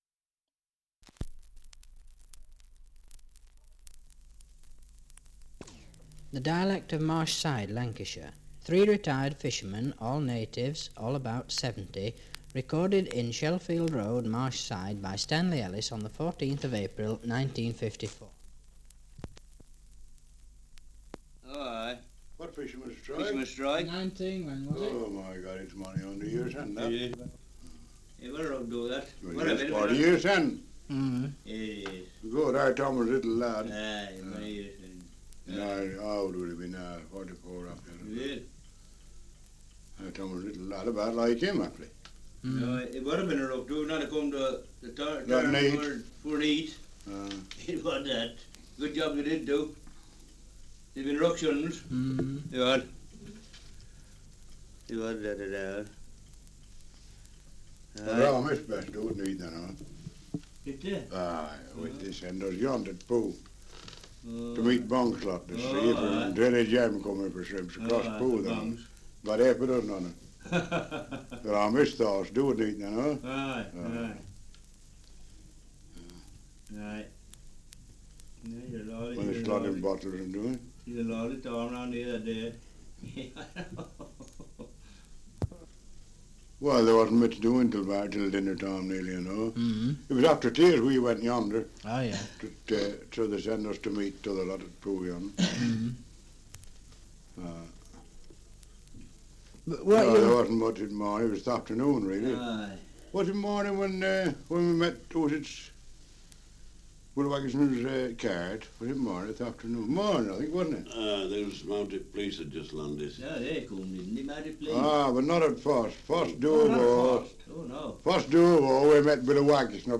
Survey of English Dialects recording in Marshside, Lancashire
78 r.p.m., cellulose nitrate on aluminium
English Language - Dialects